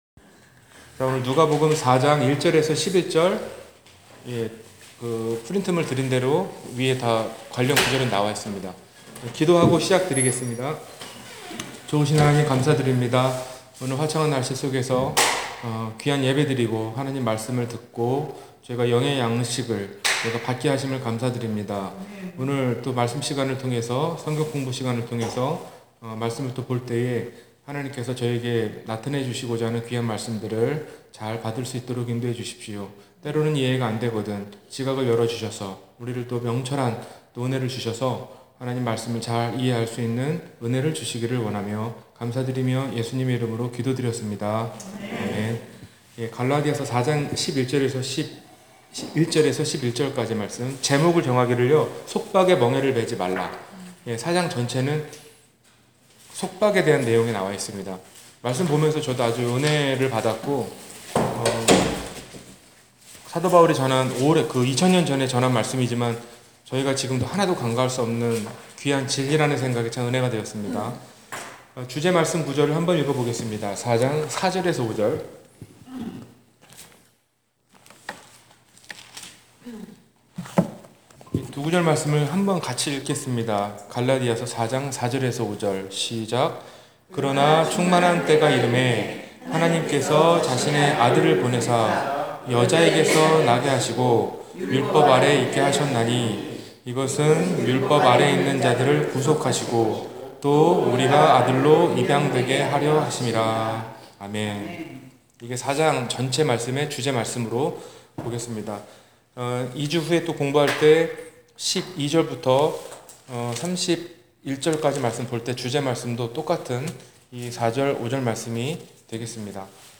갈라디아서 4장 Service Type: 주일성경공부 Bible Text